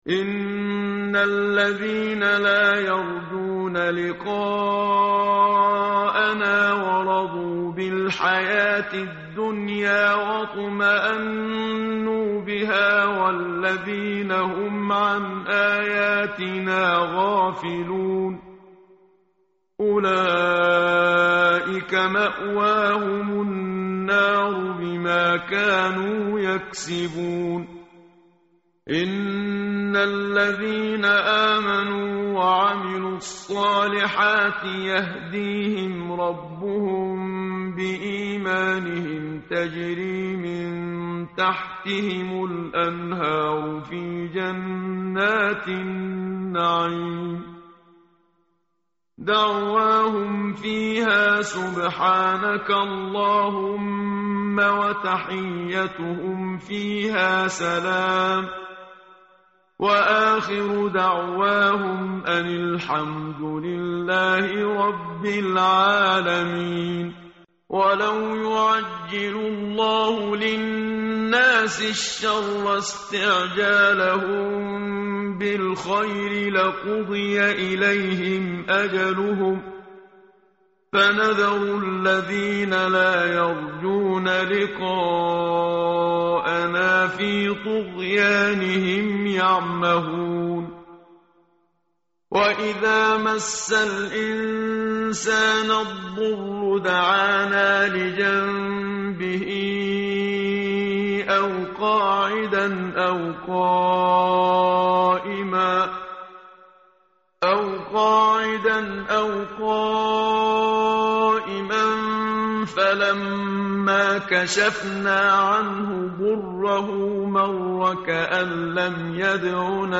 tartil_menshavi_page_209.mp3